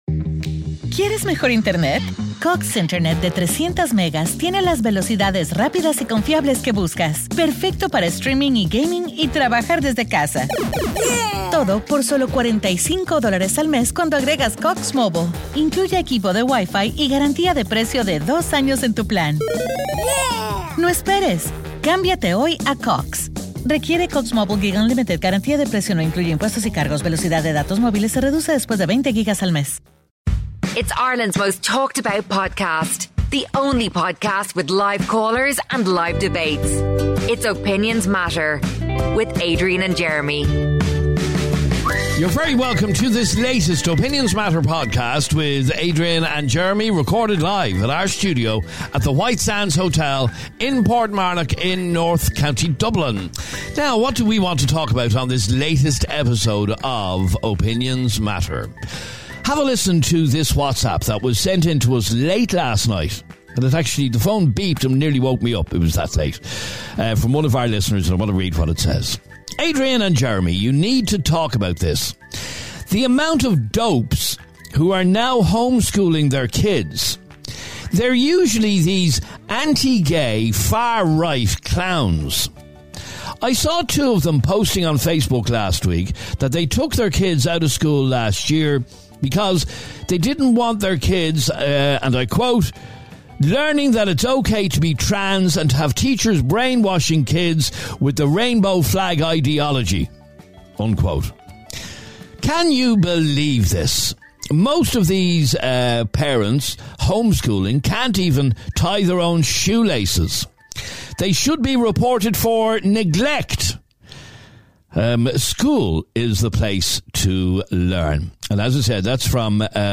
On this episode we spoke to a woman who believes that all cannabis users will eventually lead to harder drugs. She annoyed our listeners with some very harsh comments about Cannabis users.